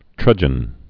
(trŭjən)